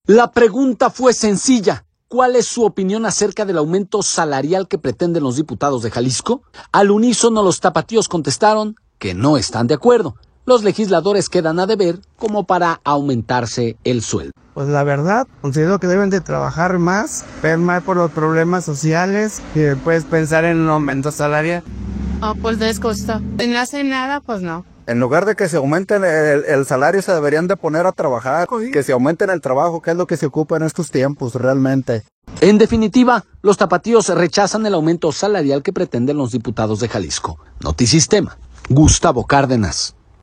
La pregunta fue sencilla: ¿cuál es su opinión acerca del aumento salarial que pretenden los diputados de Jalisco?. Al unísono, los tapatíos contestaron que no están de acuerdo, los legisladores quedan a deber como para aumentarse el sueldo.